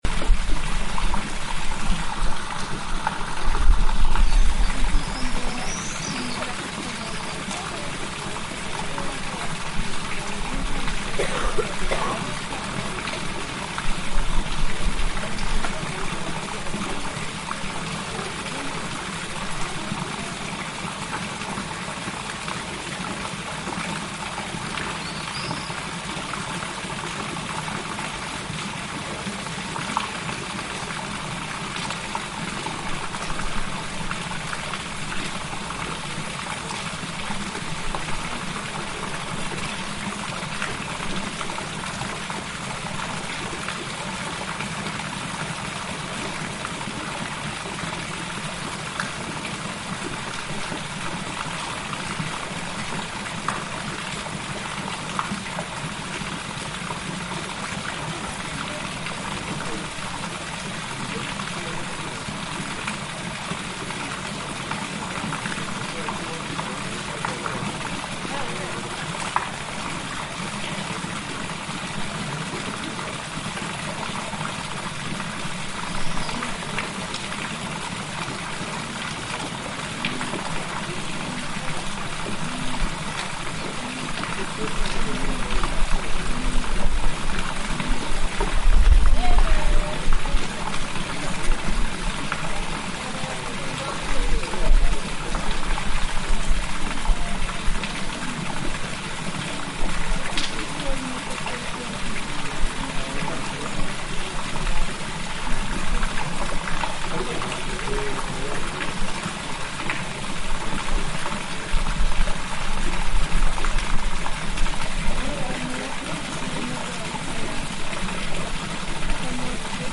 Sounds of water in the fountain of Carlos V of the Alhambra in Granada
sound of the water , sound of animals , water , Íñigo López de Mendoza y Mendoza, Fourth Count of Tendilla, Third Marquis of Mondéjar